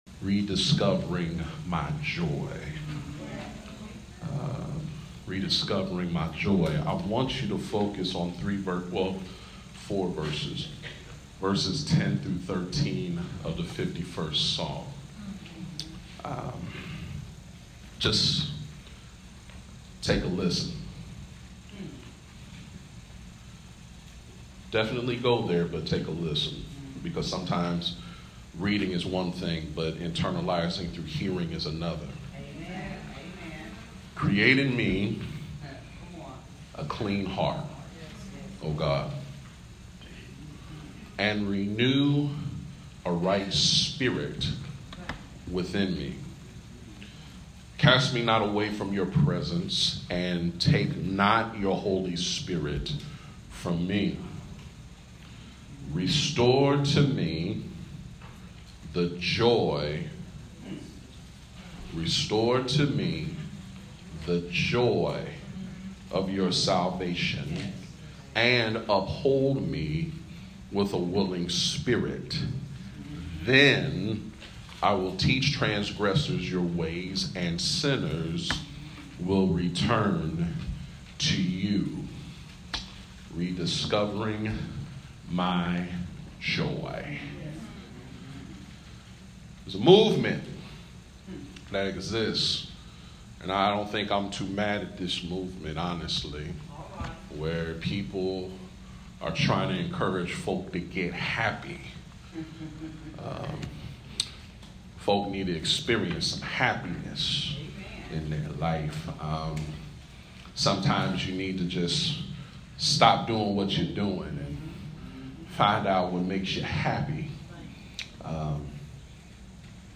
Rediscovering My Joy! Listen to Pastor’s Message from 7/29/18